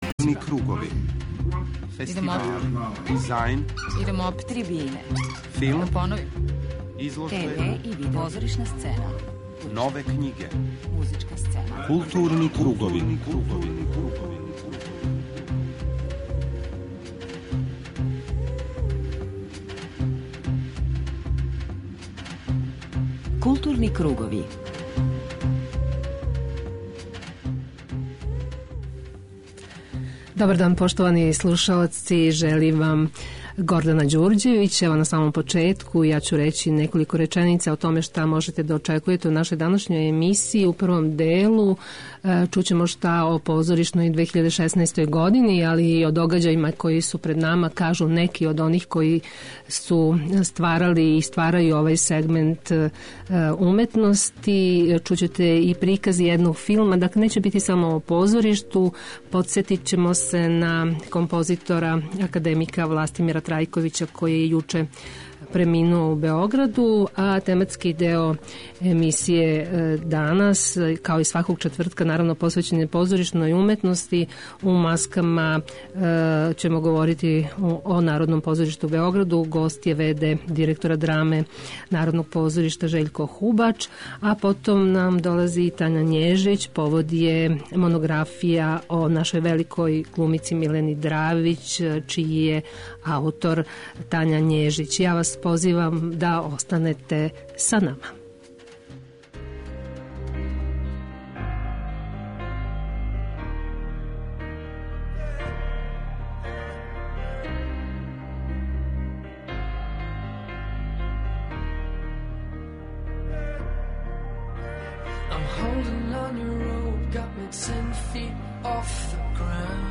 преузми : 40.85 MB Културни кругови Autor: Група аутора Централна културно-уметничка емисија Радио Београда 2.